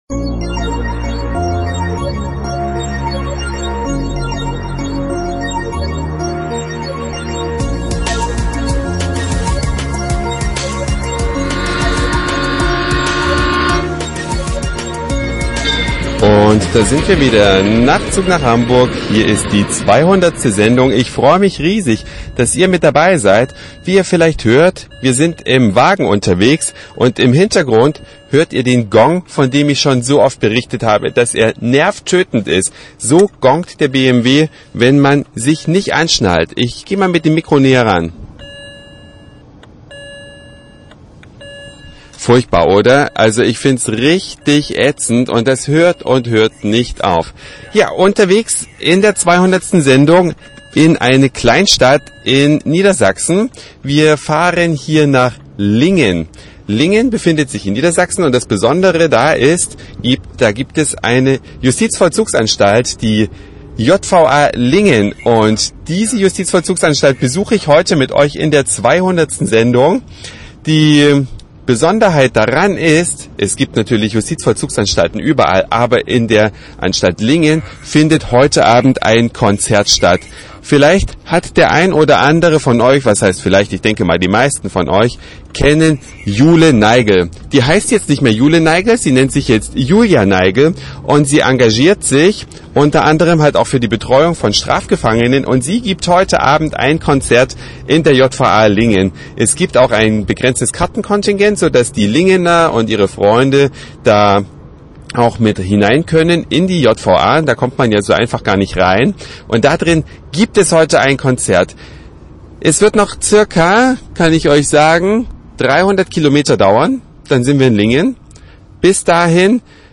Die Sonderausgabe 200. Nachtzug nach Hamburg kommt heute aus der Justizvollzugsanstalt in Lingen. Dort fan heute etwas ungewöhnliches statt: Ein Rockkonzert mit einer genialen Künstlerin innerhalb der Anstaltsmauern.